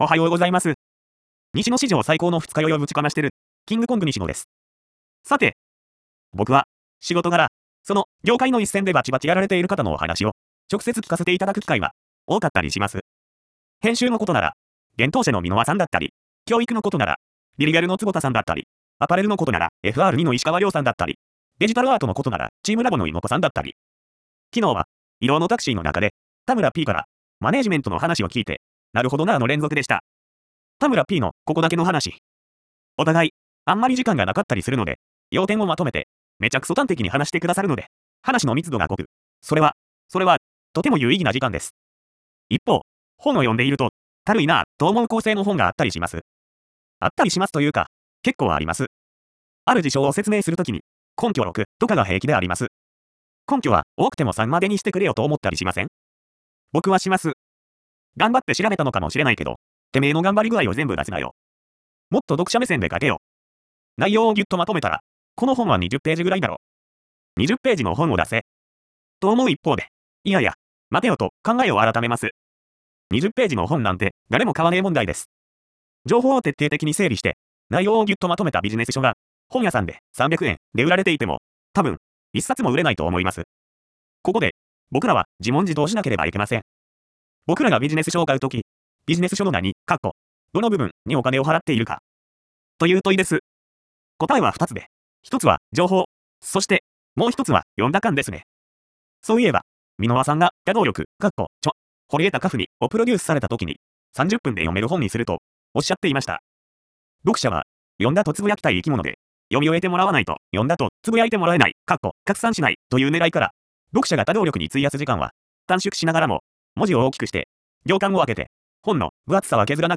上の西野亮廣さんの記事を音声コンテンツにしました。
（機械音なので聞き取りづらいところもあります。漢字の読みまちがいやご意見あればコメントお願いします）